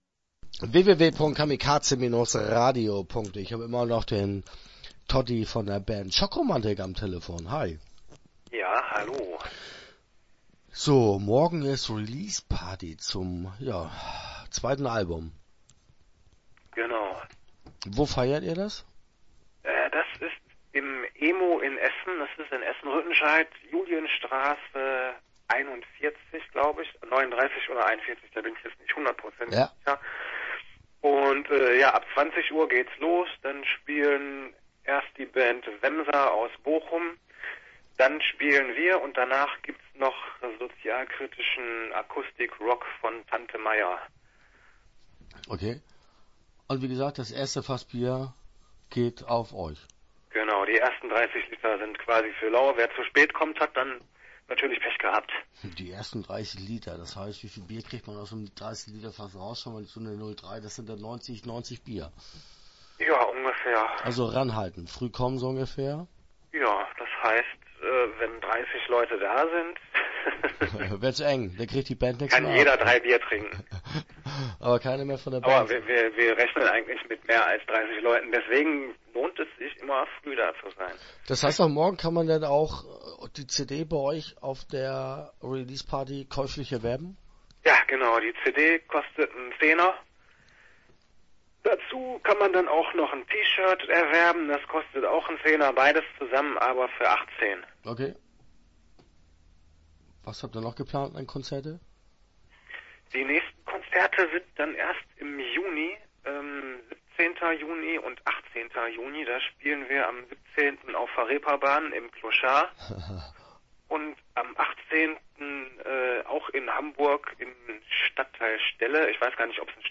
Start » Interviews » Schockromantik